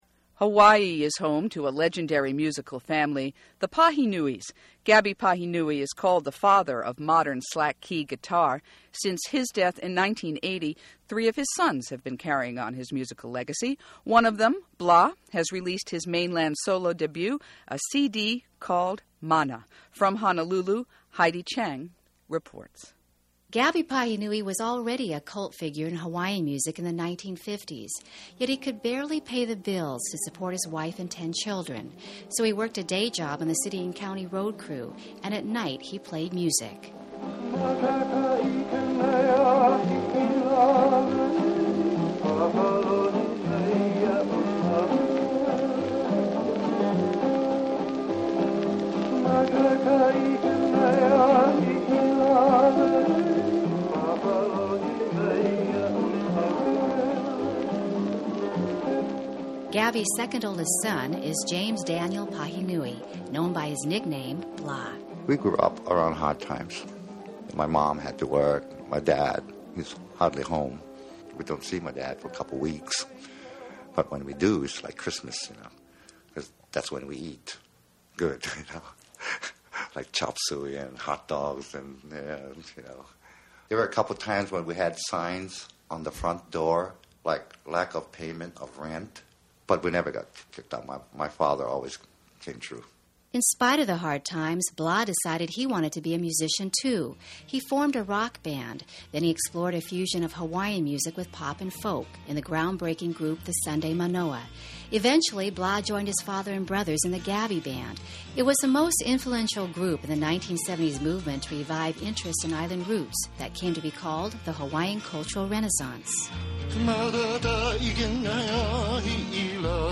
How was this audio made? Aired on NPR’s “Weekend Edition Sunday,” November 2, 1997